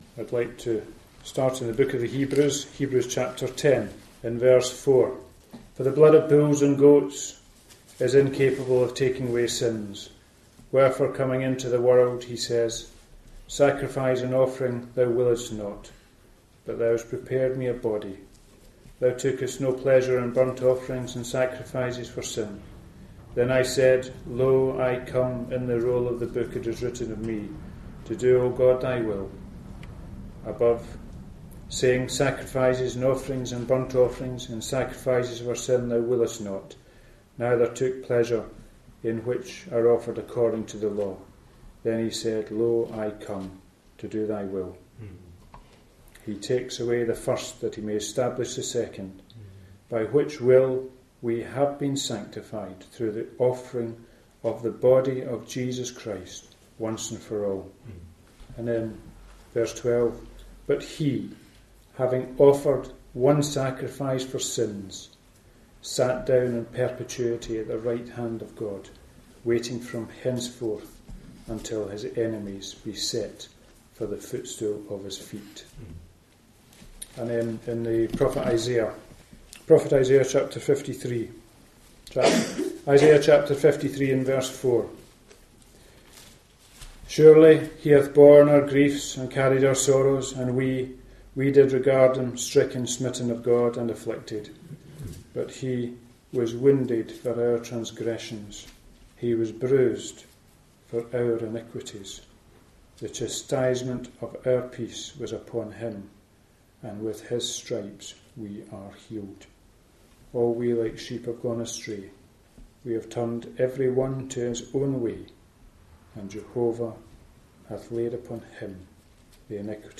The Lord Jesus has done a very wonderful work to rescue the sinner from perishing. In this Gospel preaching, you will hear of what the Lord Jesus went through to secure salvation for mankind.